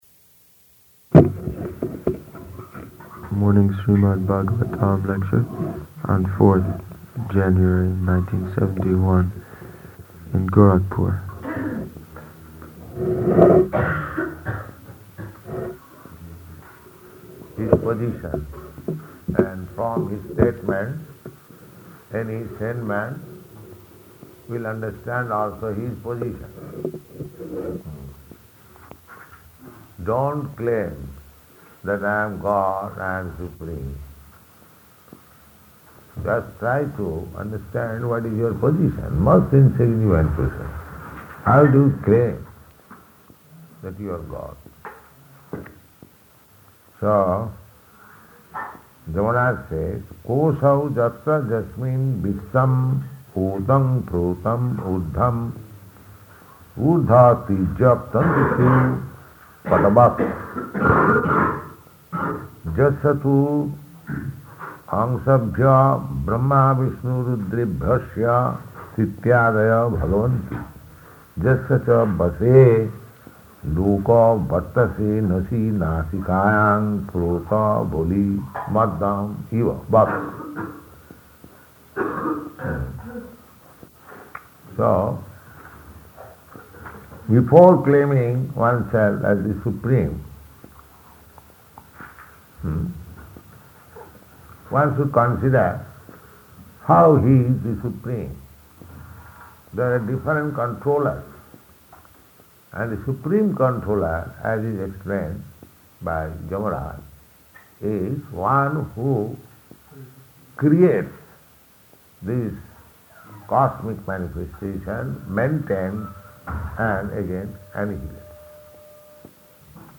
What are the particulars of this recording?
Śrīmad-Bhāgavatam 6.3.12–15 --:-- --:-- Type: Srimad-Bhagavatam Dated: February 4th 1971 Location: Gorakphur Audio file: 710204SB-GORAKPHUR.mp3 Devotee: [introducing recording] Morning Śrīmad-Bhāgavatam lecture on 4th January [sic], 1971, in Gorakhpur.